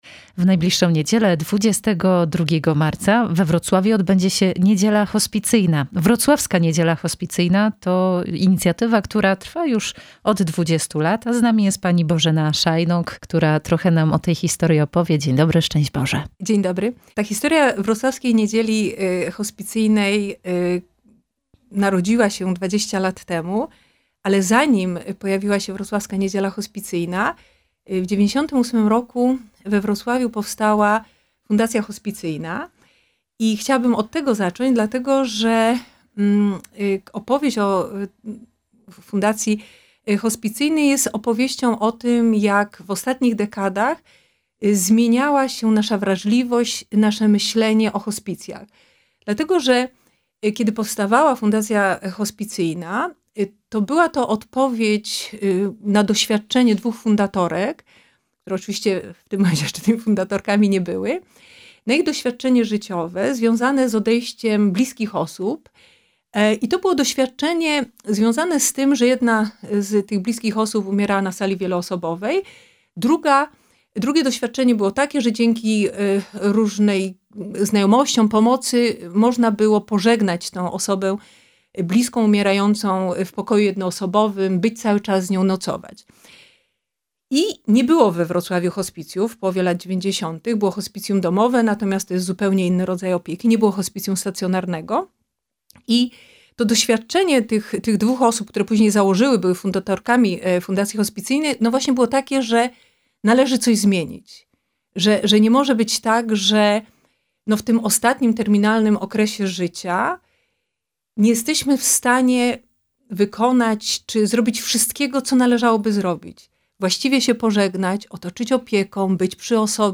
Gościem Radia Rodzina jest